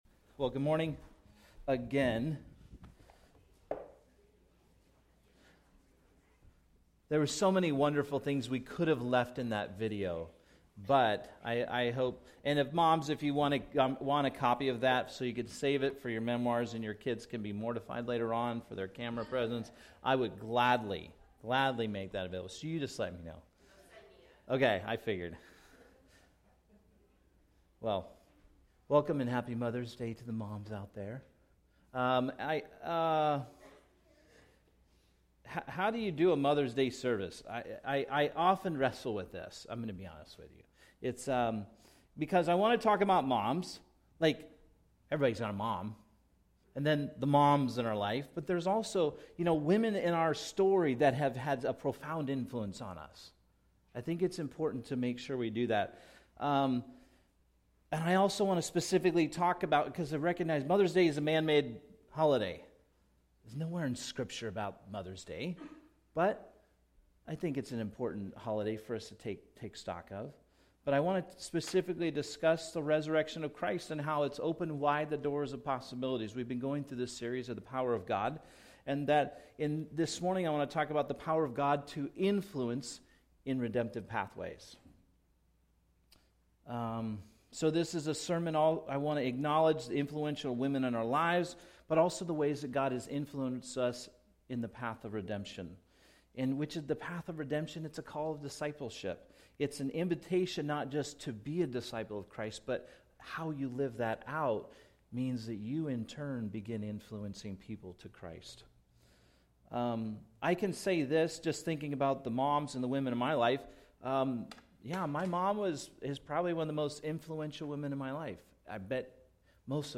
A message from the series "Hot Topics." How do Christians engage in topics related to faith and politics